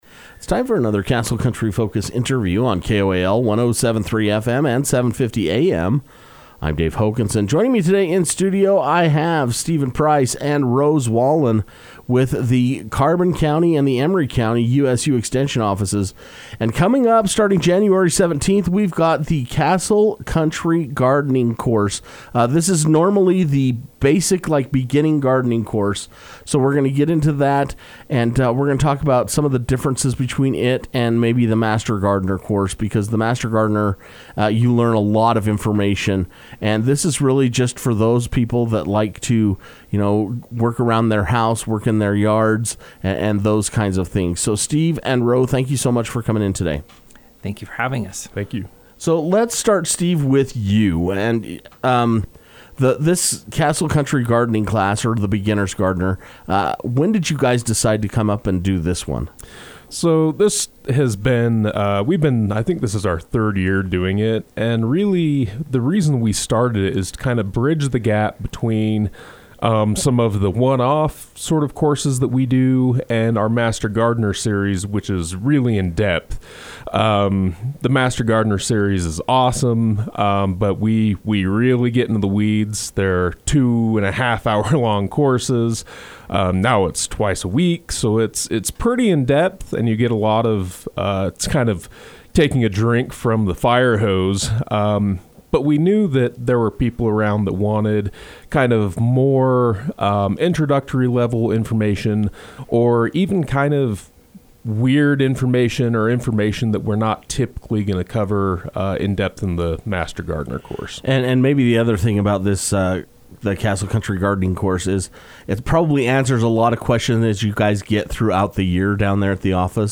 The USU Extension is excited to be hosting a Castle Country Gardening Course set to begin on Jan. 17 and this is a great series of introductory level gardening classes to help growers be successful in their gardens. Castle Country Radio was able to sit down with Extension Assistant Professors